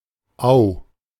Au (German pronunciation: [aʊ]